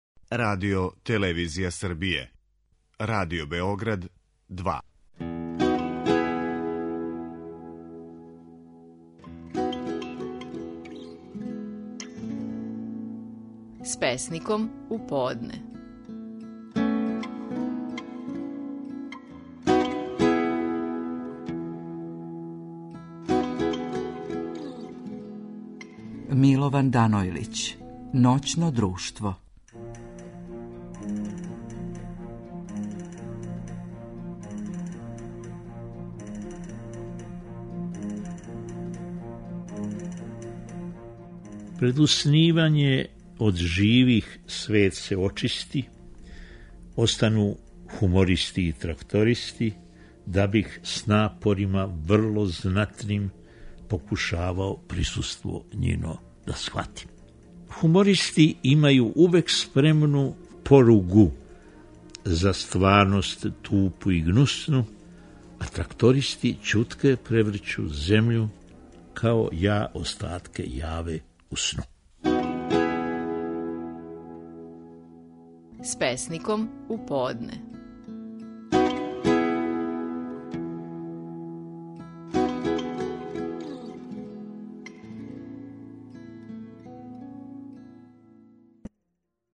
Наши најпознатији песници говоре своје стихове